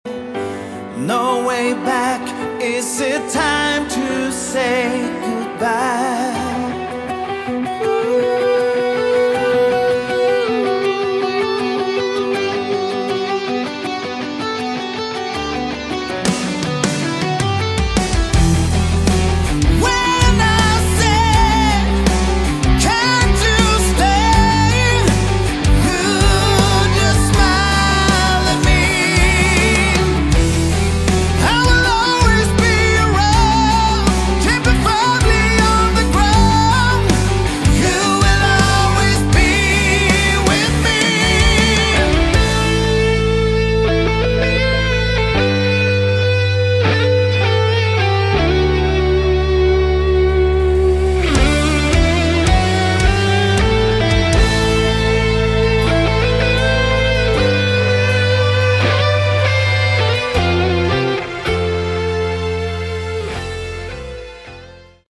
Category: Melodic Hard Rock
vocals
guitars
keyboards
bass
drums